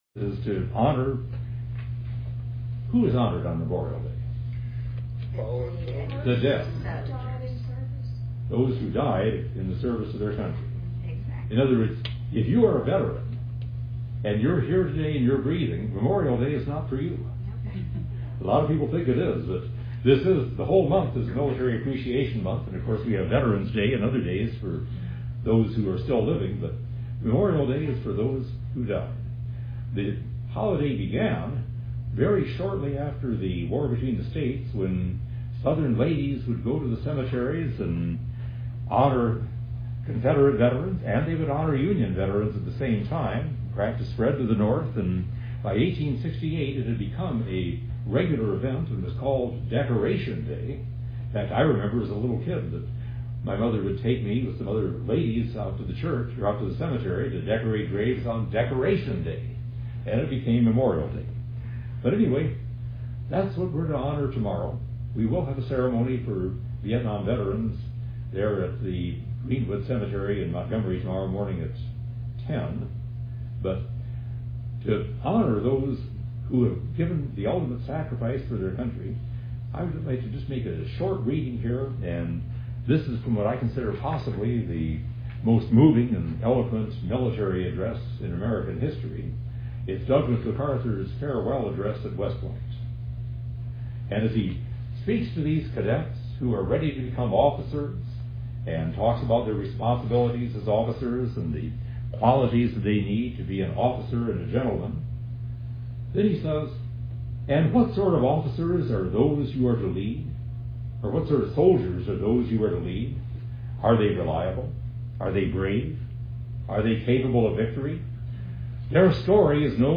— May 26, 2024 – Woodland Presbyterian Church